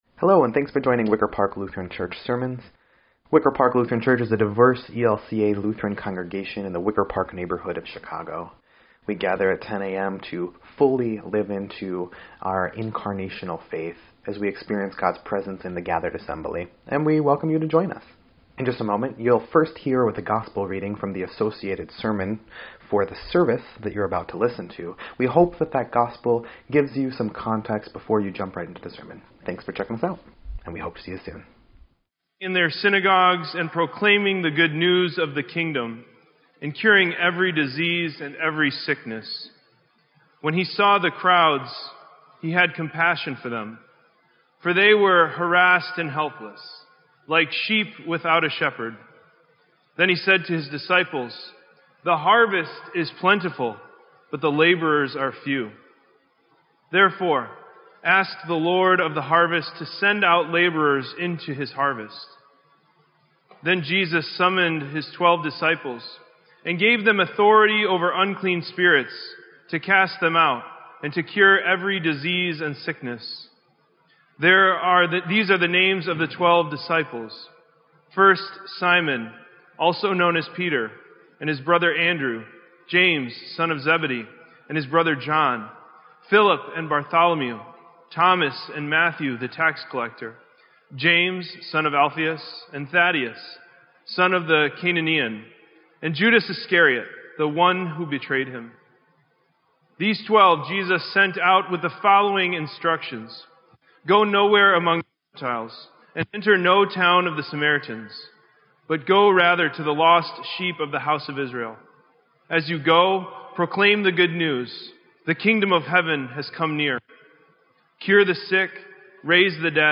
Sermon_6_18_17_EDIT.mp3